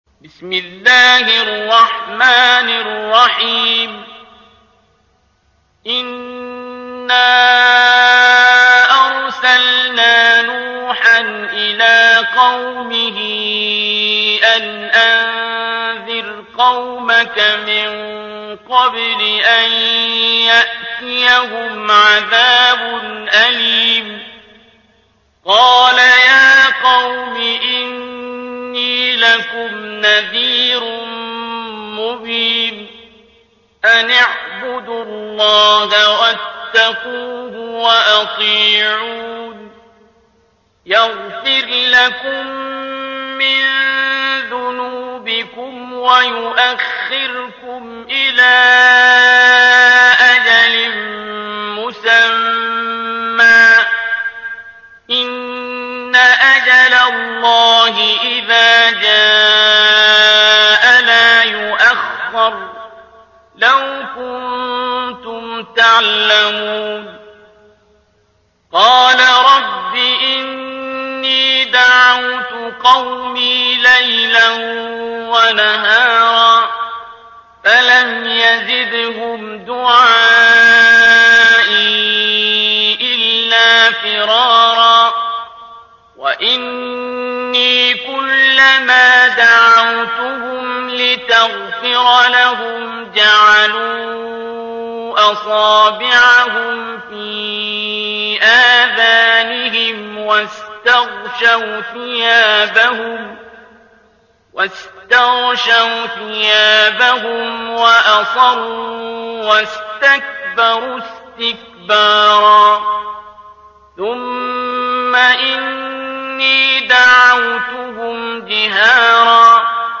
ترتيل